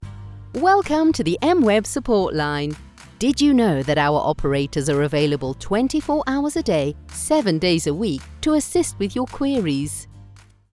Mi acento inglés neutro funciona bien en los mercados internacionales.
Mi voz es natural y amigable, pero resonante y autoritaria. A mis clientes también les encanta mi voz seductora.
Micrófono Audio Technica AT2020